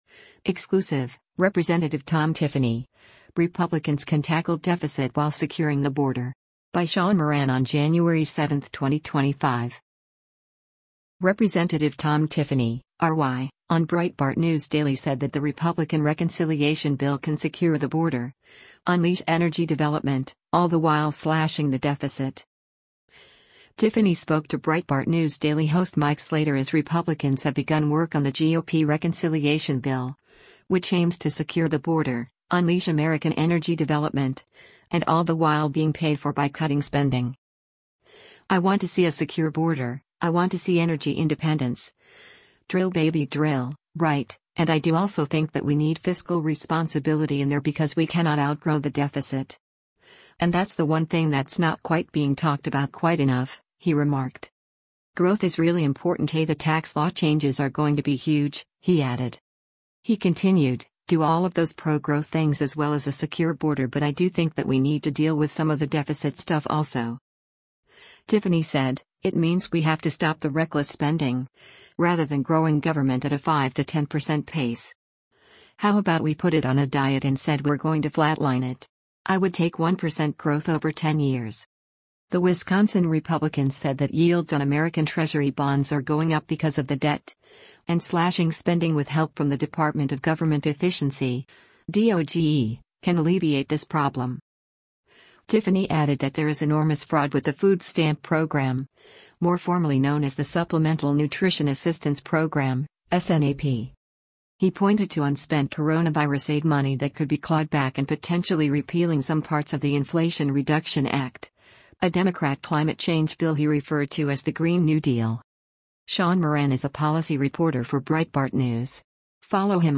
Rep. Tom Tiffany (R-WI) on Breitbart News Daily said that the Republican reconciliation bill can secure the border, unleash energy development, all the while slashing the deficit.